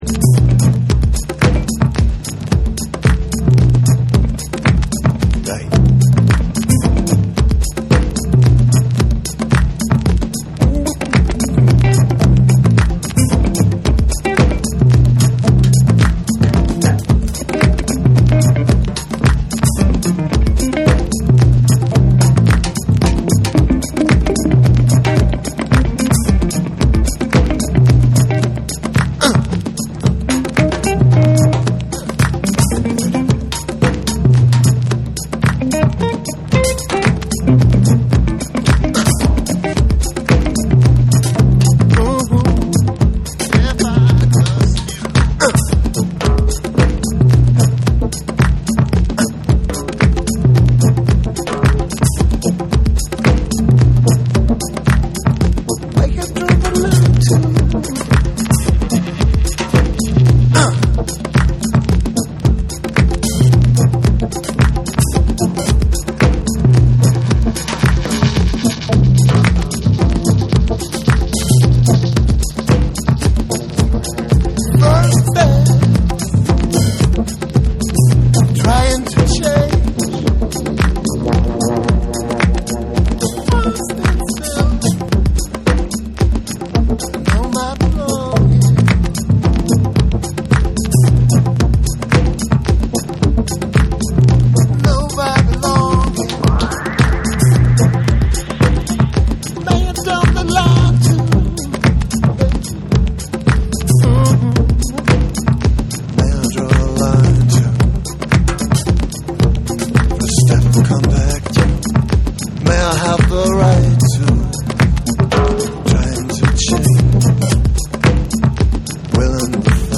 TECHNO & HOUSE / ORGANIC GROOVE